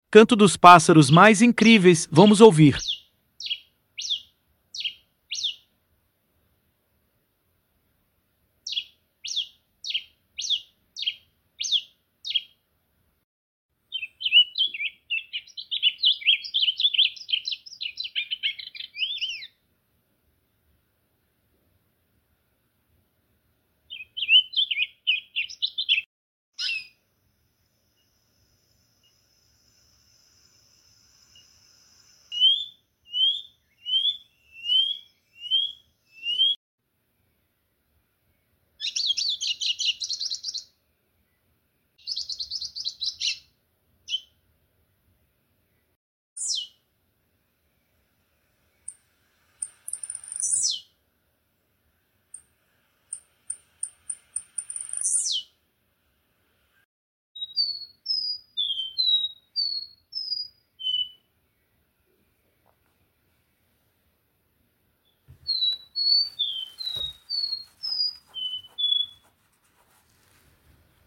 canto dos pássaros mais lindo sound effects free download